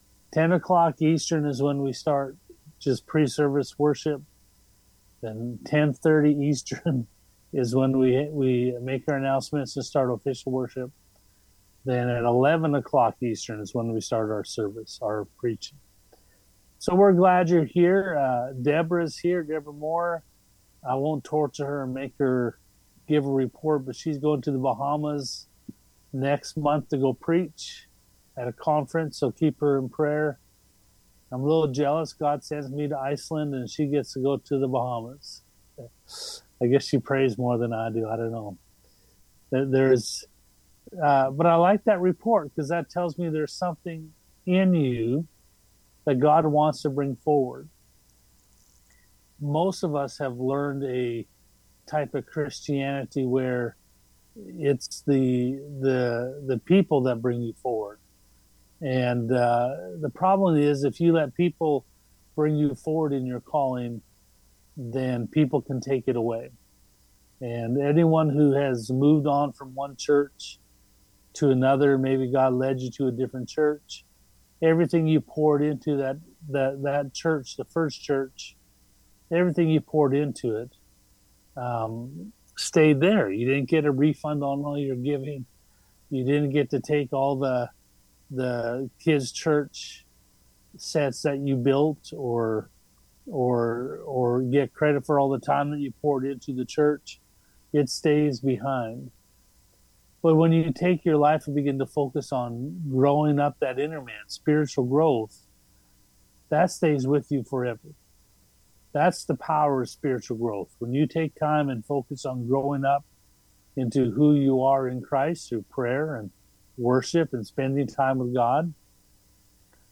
Weekly Sermons - Family Prayer Centre
Sunday Morning Service